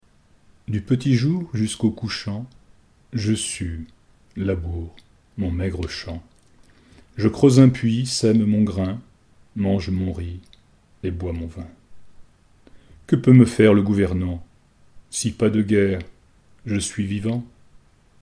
SlowReading
* SlowReading : lecture aux lèvres, qui ralentit une pensée toujours pressée et galopante